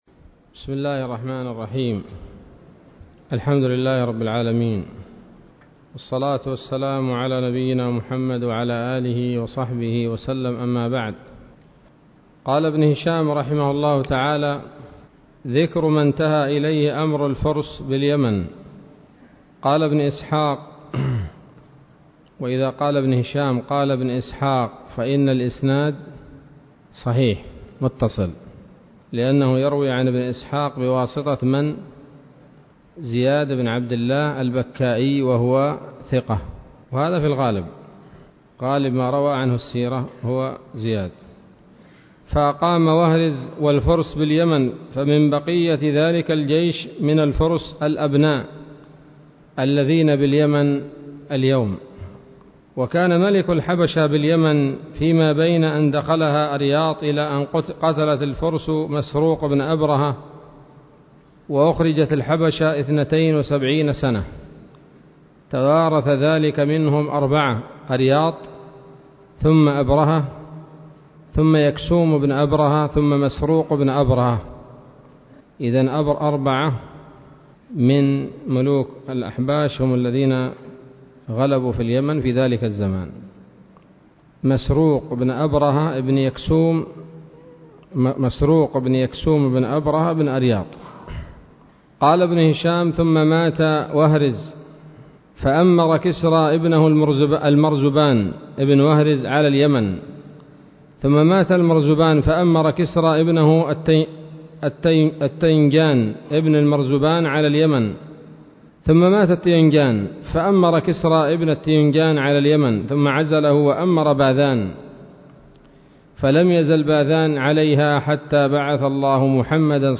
الدرس السابع من التعليق على كتاب السيرة النبوية لابن هشام